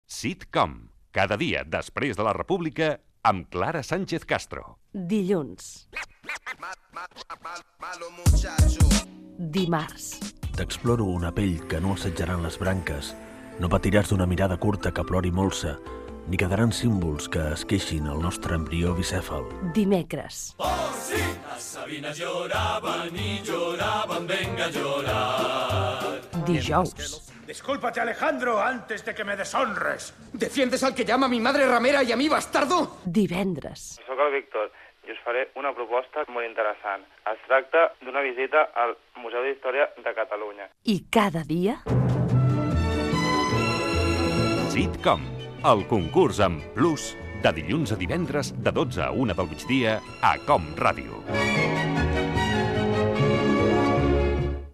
Promoció del programa "Sitcom", amb un resum de conringuts de cada dia